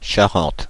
The Charente (French: [ʃaʁɑ̃t]
Fr-Paris--Charente.ogg.mp3